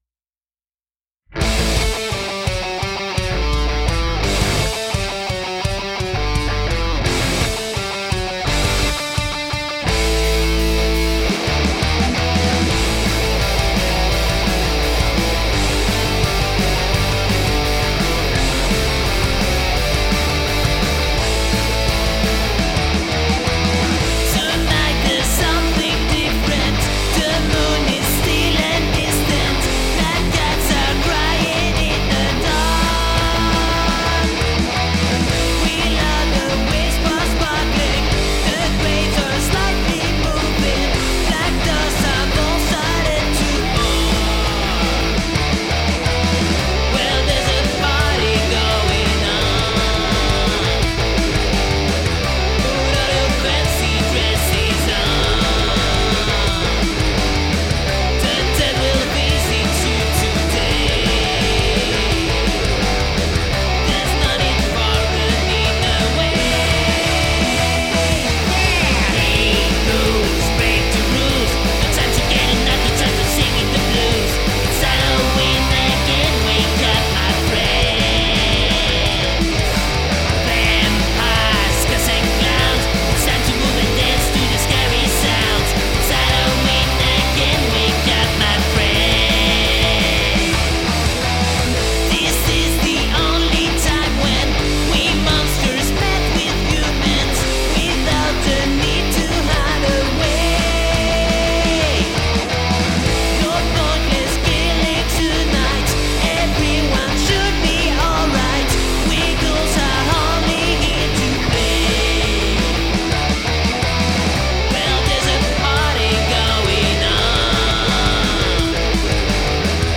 Drums, Bass, Guitar & Vocals
glam-metal-meets-garage-rock musical project